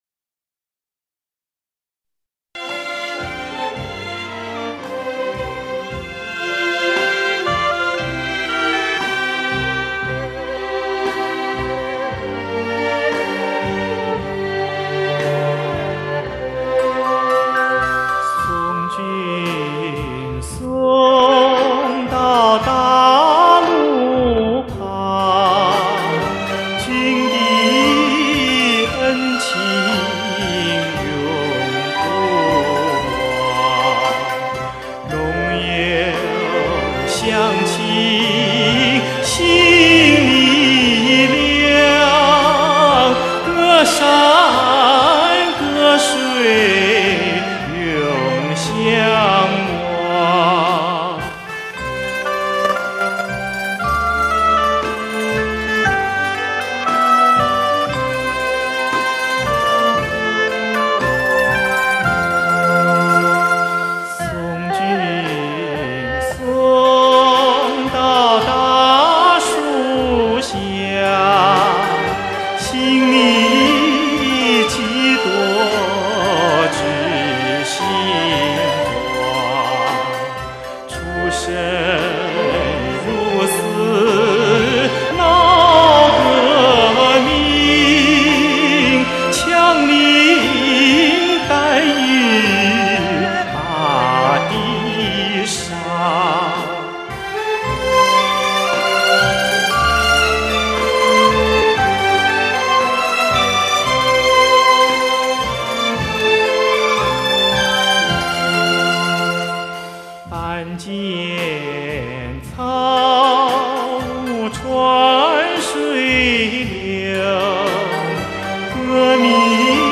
最浑厚的男声 零距离的耳朵盛宴
享誉业界的终极的现场演唱效果